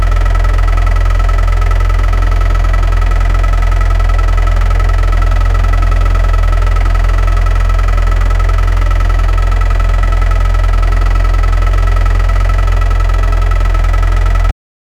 Toyota GT86, engine sound (idle, exhaust, engine, redlineú 0:05 Created Oct 19, 2024 5:13 PM Volvo b230F Idle engine sound 0:15 Created Dec 5, 2024 6:50 PM V8 Engine Magnum! 0:05 Created Oct 26, 2024 8:04 PM
volvo-b230f-idle-engine-s-4kokxrlk.wav